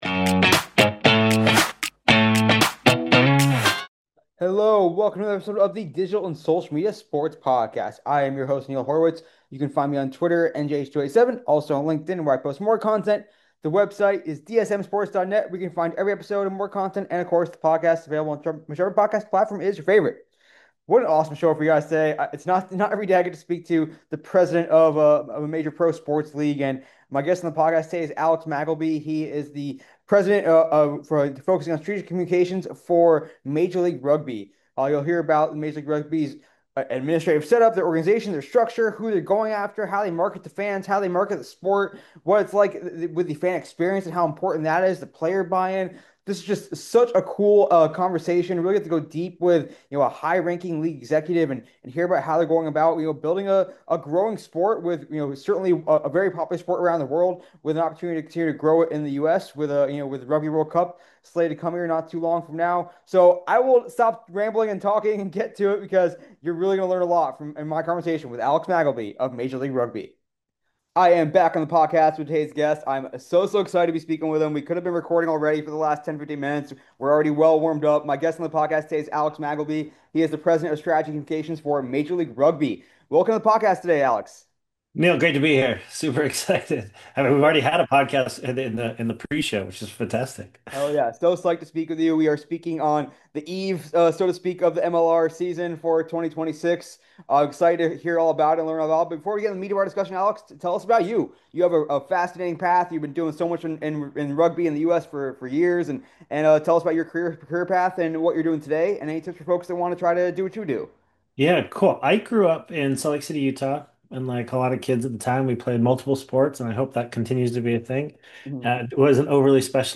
A business-minded look at digital and social media in sports, featuring interviews with pros and thought leaders in the space.